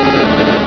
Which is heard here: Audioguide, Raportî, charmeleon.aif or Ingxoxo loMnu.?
charmeleon.aif